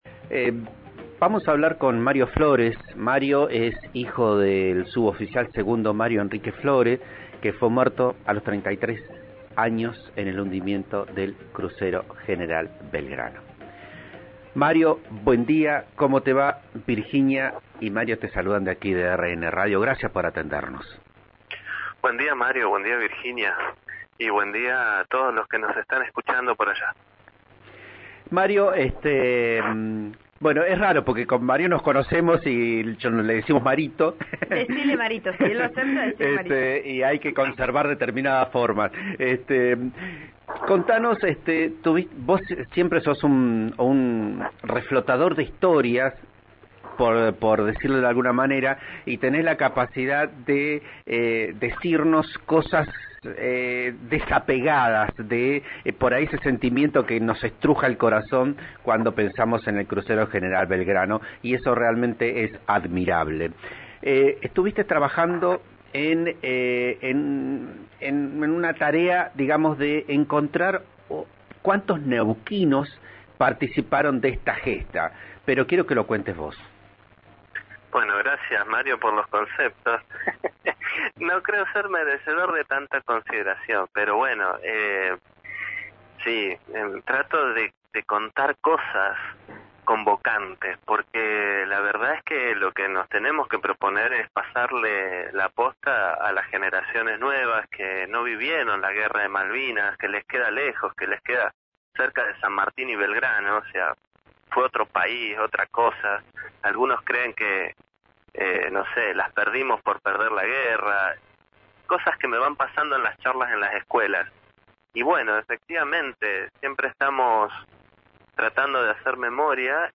En “Vos a Diario” por RN Radio contó sobre su trabajo para difundir todo lo vinculado a Malvinas, quiénes son los combatientes de Neuquén y el rol de las mujeres.